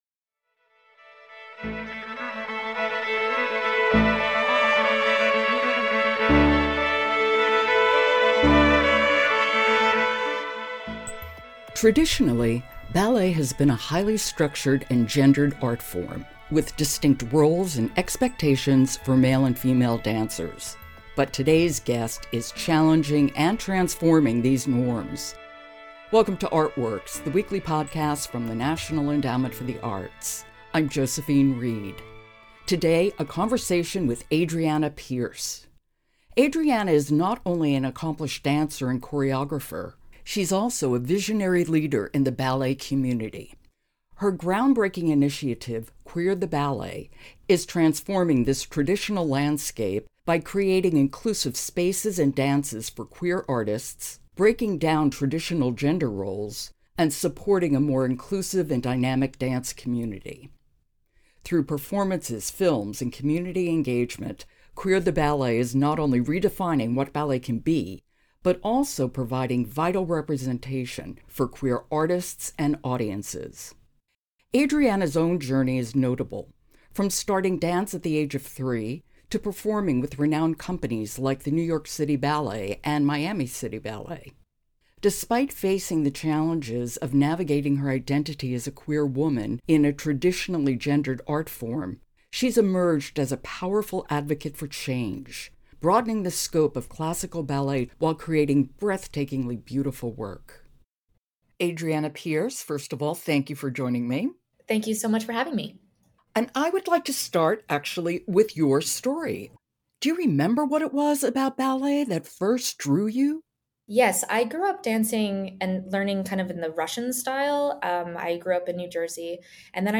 But today’s guest is challenging and transforming these norms.